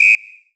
LWHISTLE 1.wav